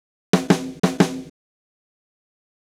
Drumset Fill 21.wav